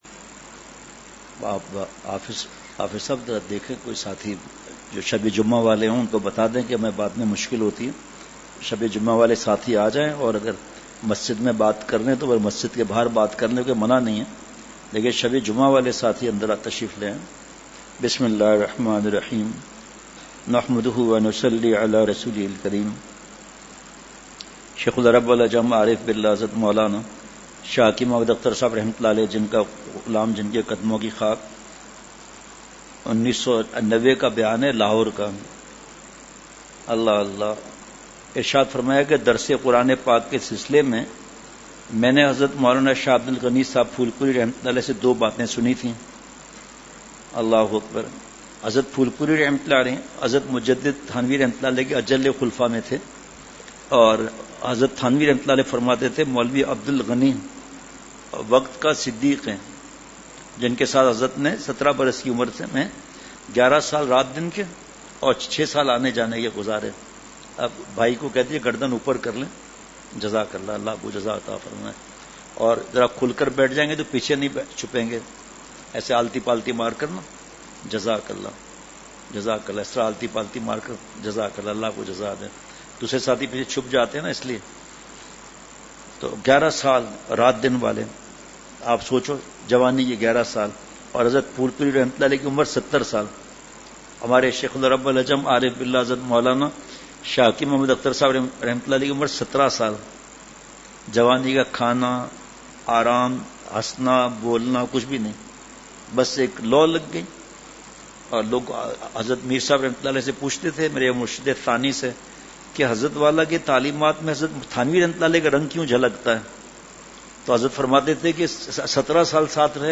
اصلاحی مجلس کی جھلکیاں مقام:مسجد اختر نزد سندھ بلوچ سوسائٹی گلستانِ جوہر کراچی